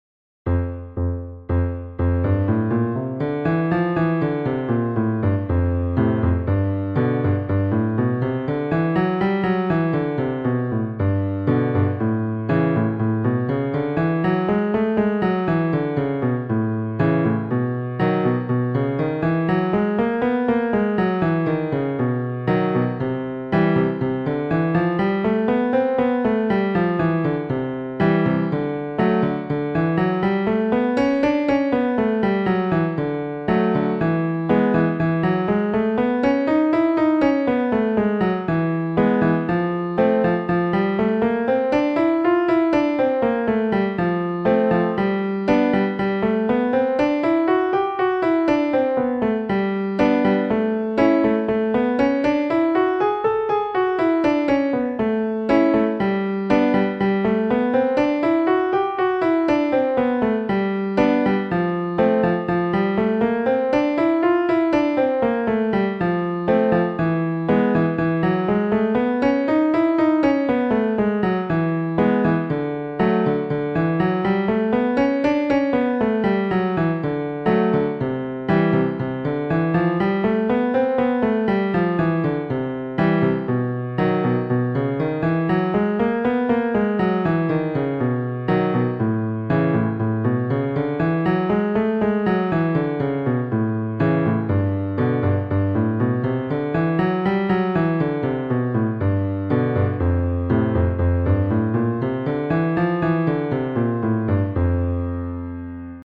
Free Male Vocal Scales -
Ex2.4 – male major scale f2 – a4 chest and mixed
Ex2.4.m.F1_-_A3_M_Major_Scale.mp3